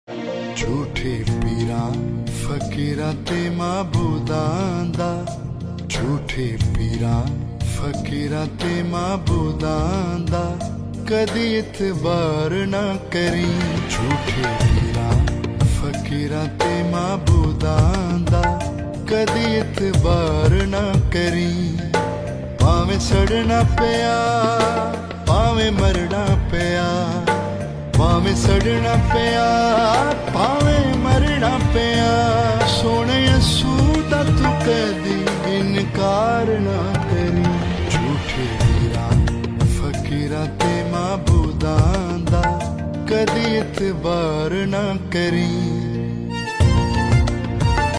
New Masihi Geet